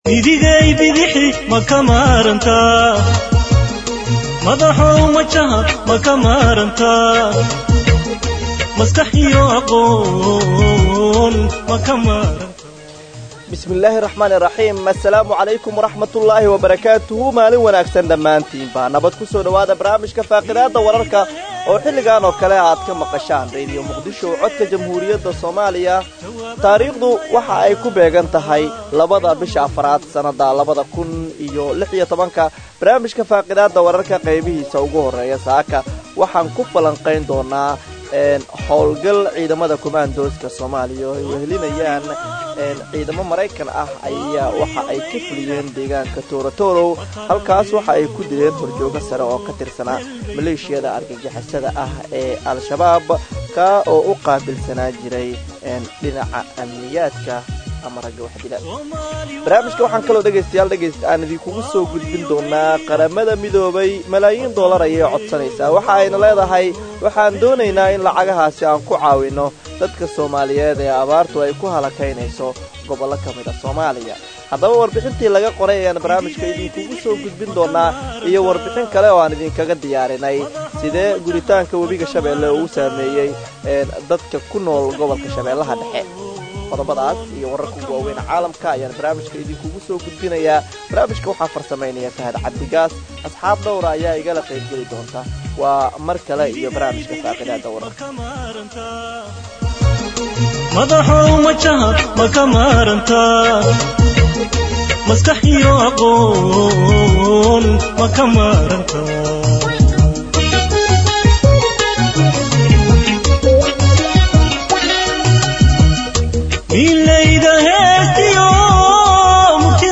Akhristayaasheena sharafta leh waxaan halkaan idinkugu soo gudbineynaa barnaamijka Faaqidaada oo ka baxa Radio Muqdisho subax waliba marka laga reebo subaxda Jimcaha, waxaana uu xambaarsanyahay macluumaad u badan wareysiyo iyo falaqeyn xagga wararka ka baxa Idaacadda, kuwooda ugu xiisaha badan.